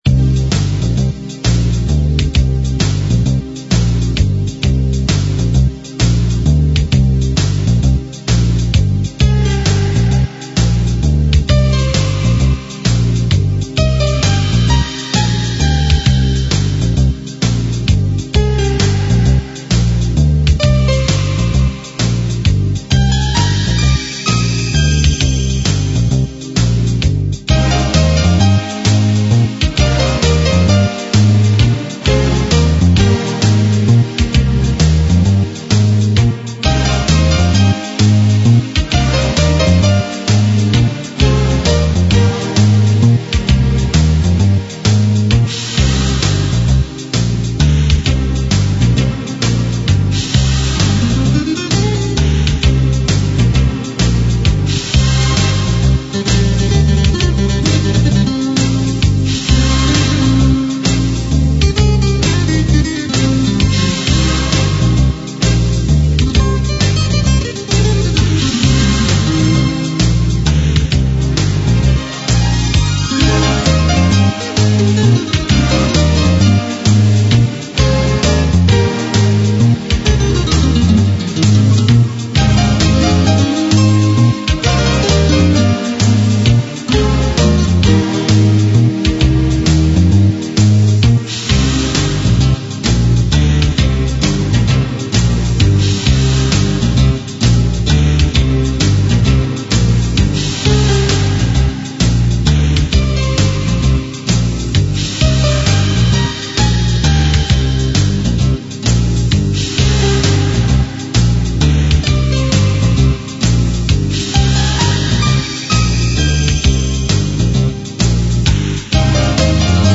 5/4, středně rychle, orchestr, španělka